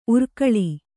♪ urkaḷi